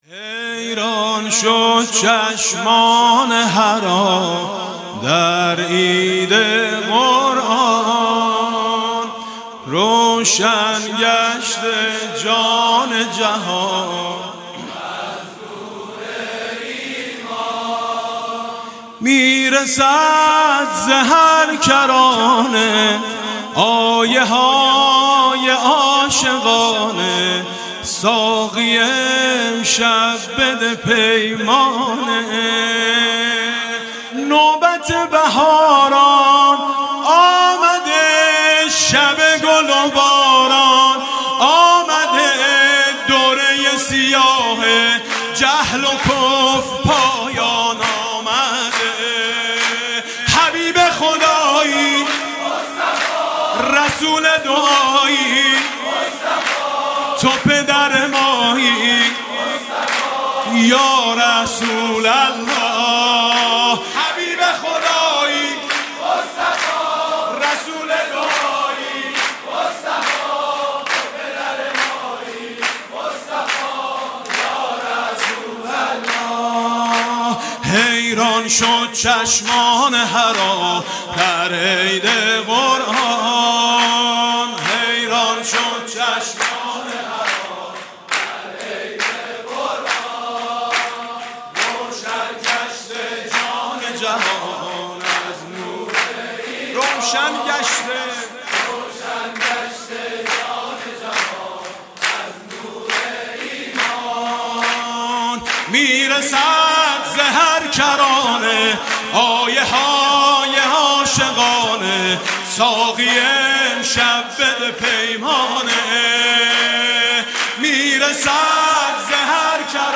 مولودی